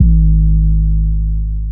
kits/TM88/808s/8081.wav at main